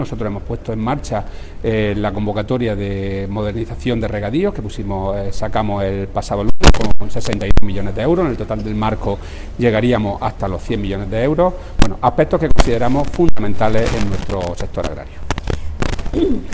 El consejero en la presentación del informe ‘Claves para la transformación del sector agroalimentario andaluz’ de la consultora Price Waterhouse Cooper
Declaraciones de Rodrigo Sánchez Haro sobre el informe ‘Claves para la transformación del sector agroalimentario andaluz’ de la consultora Price Waterhouse Cooper